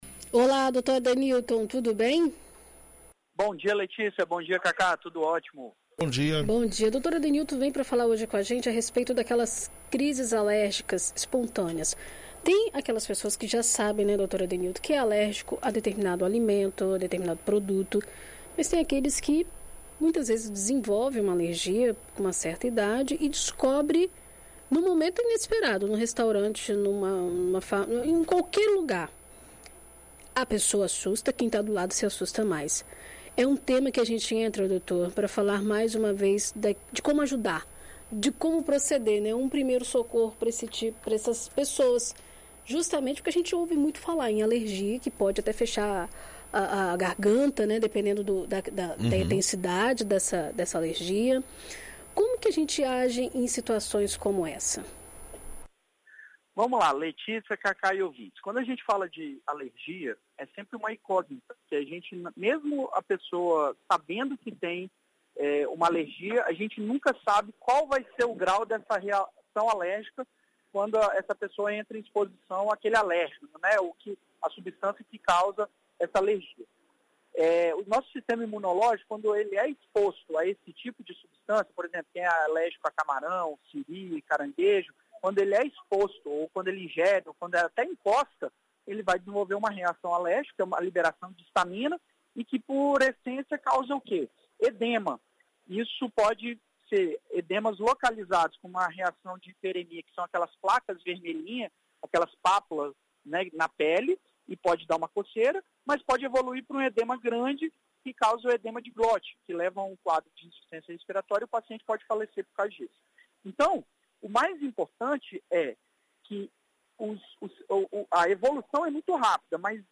Na coluna desta semana na BandNews FM Espírito Santo